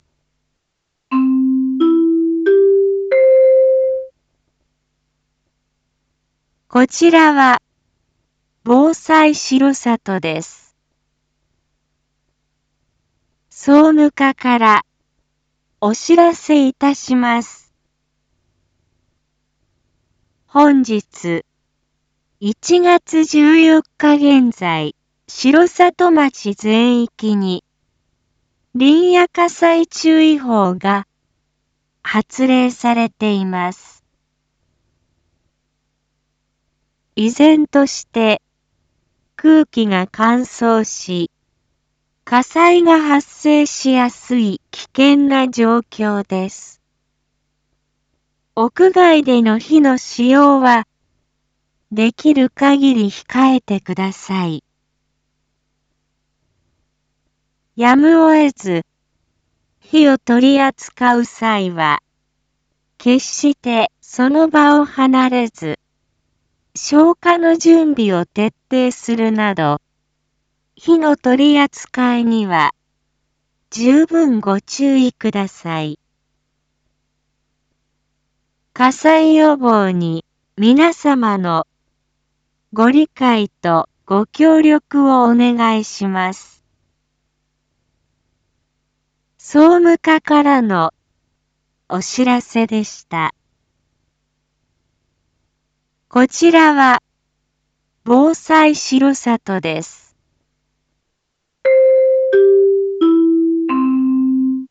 Back Home 一般放送情報 音声放送 再生 一般放送情報 登録日時：2026-01-14 14:31:46 タイトル：「火災警報」から「林野火災注意報」に切り替わりました インフォメーション：現在、空気が乾燥し、火災が発生しやすい状況のため、令和8年1月14日9時00分に「林野火災注意報」が町内全域に発令されました。